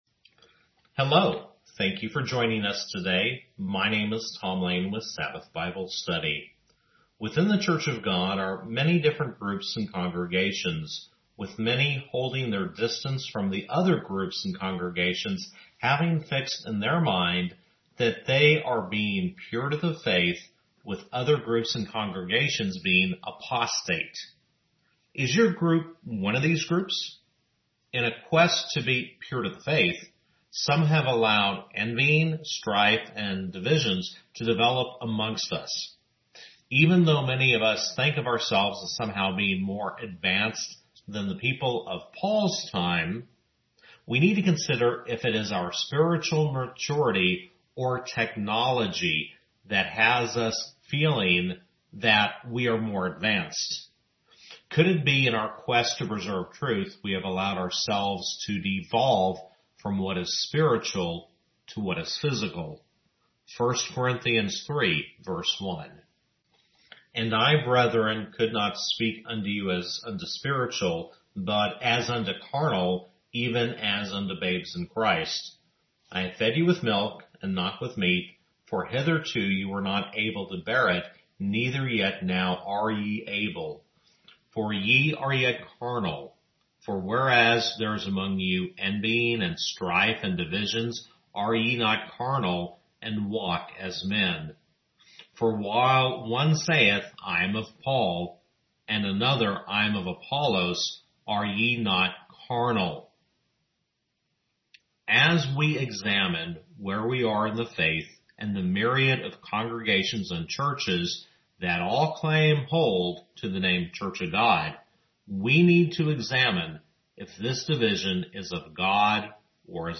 Sabbath Bible Study-2022 Sermons